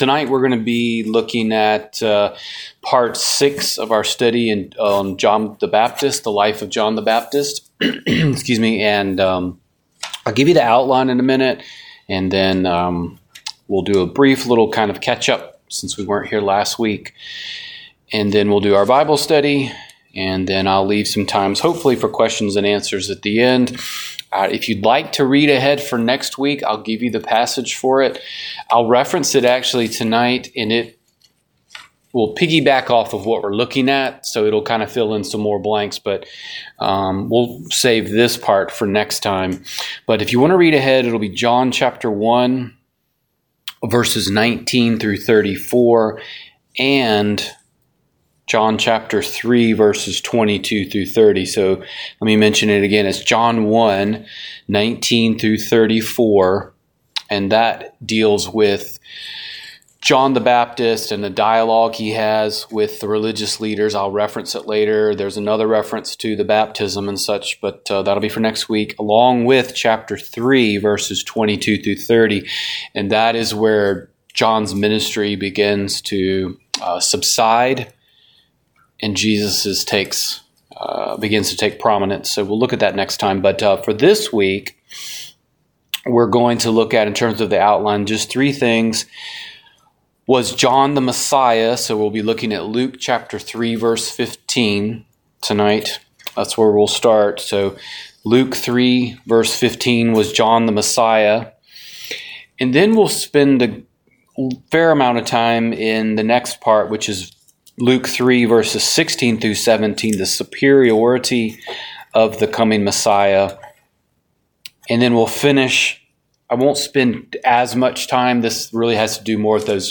Download Download The Life of John the Baptist - Part 6 Wed. Night Bible Study The Life of Stephen - Part 4 Wed.